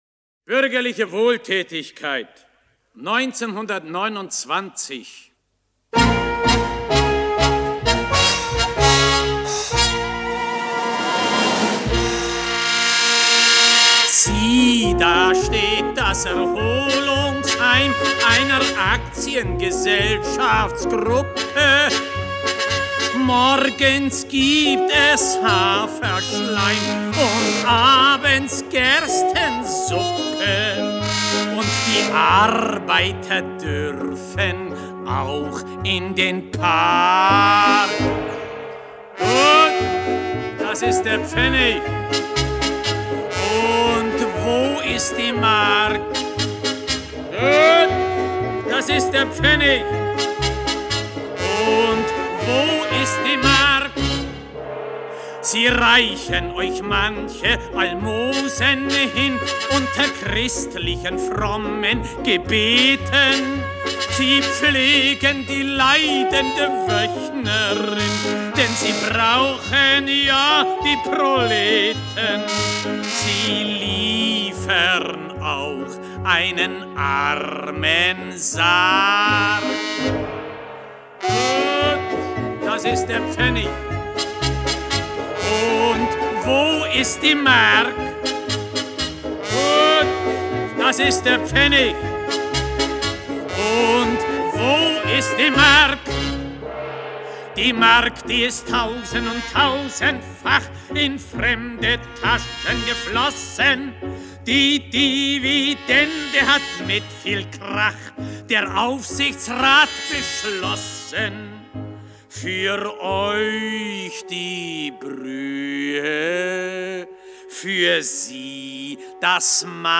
Исполнение 1960-х гг.